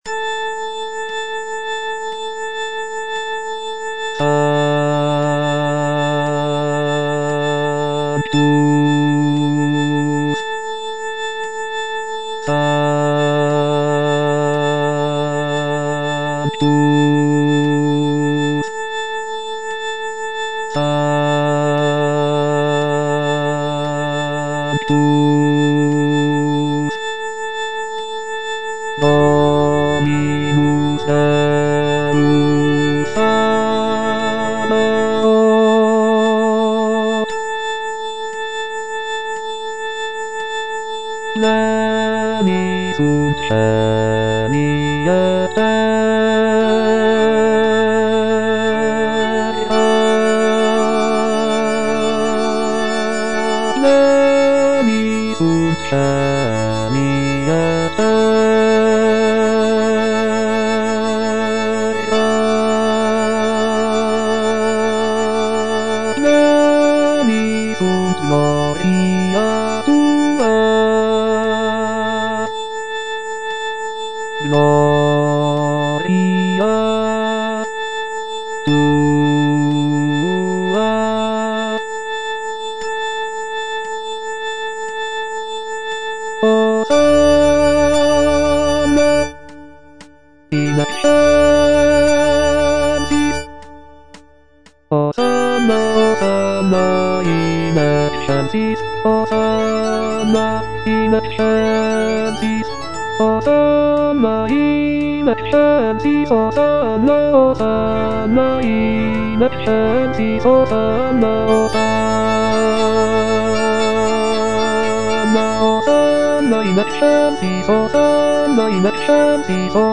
F. VON SUPPÈ - MISSA PRO DEFUNCTIS/REQUIEM Sanctus (bass II) (Voice with metronome) Ads stop: auto-stop